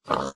pig1.ogg